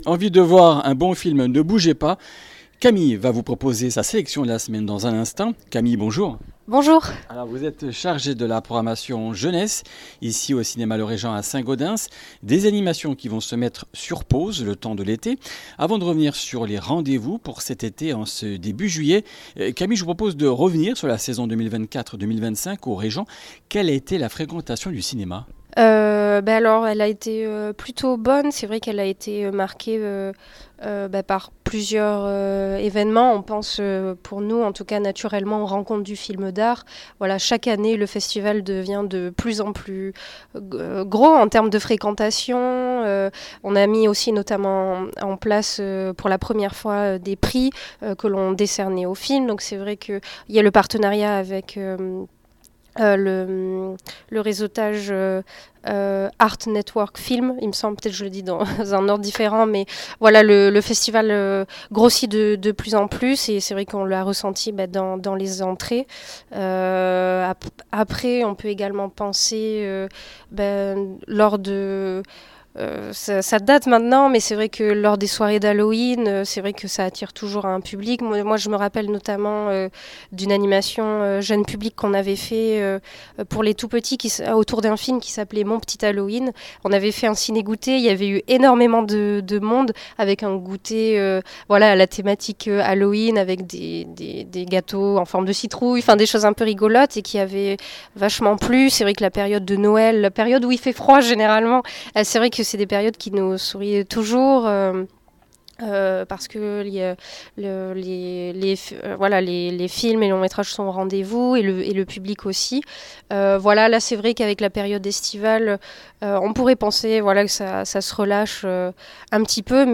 Comminges Interviews du 03 juil.
Une émission présentée par